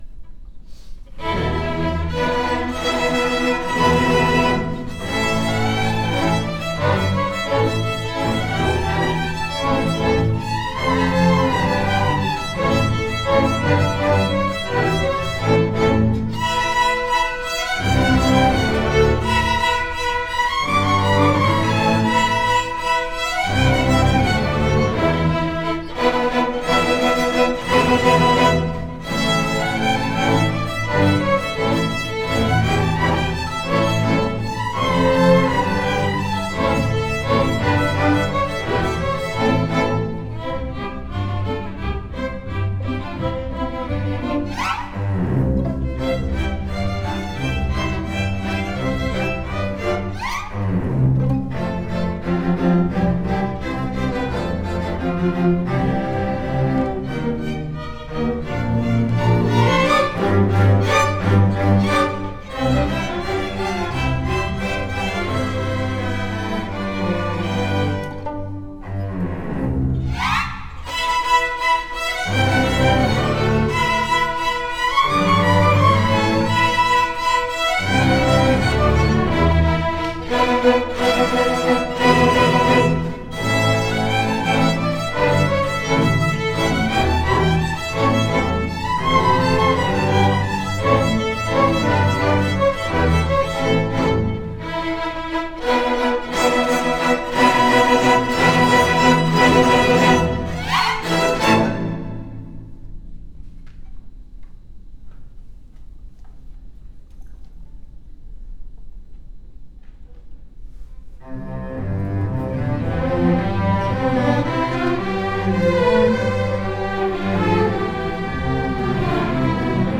Orchestra District MPA 2020 – March 10 – 12 at Largo High School